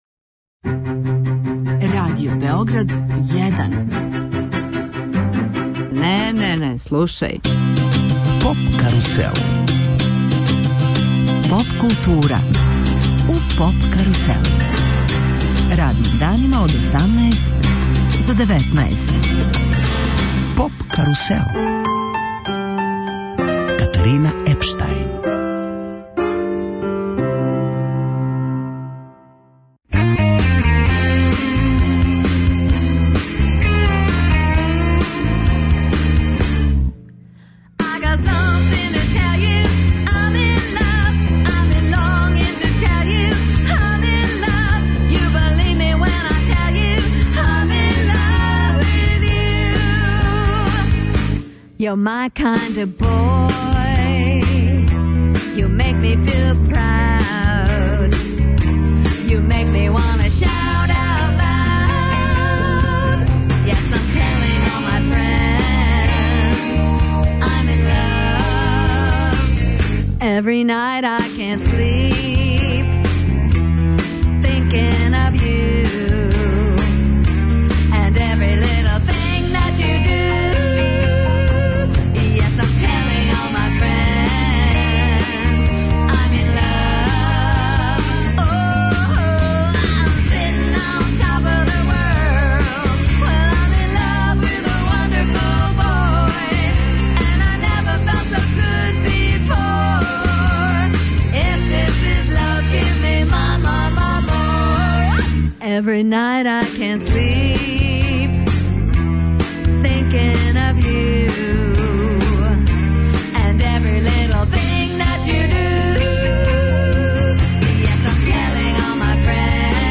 Београдски састав Magic Bush одржаће мини концерт у оквиру емисије.